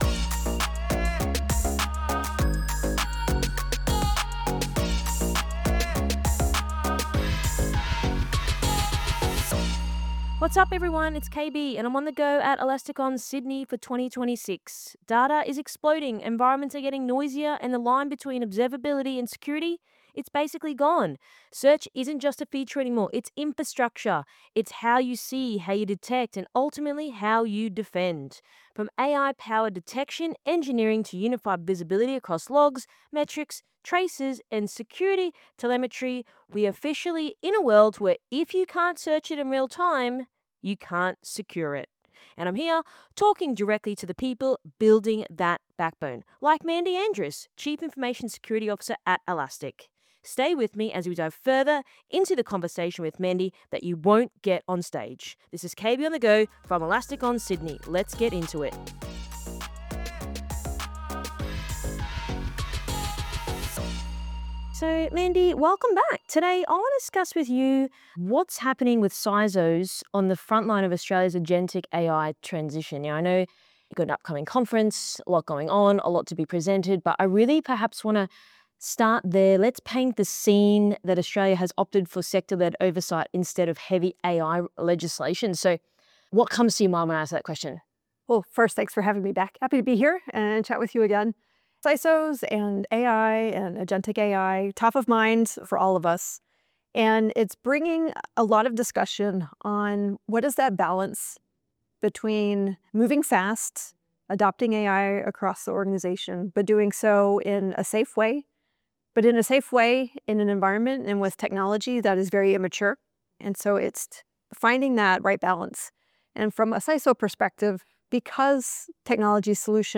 From Elastic{ON} Sydney 2026 – KB on the Go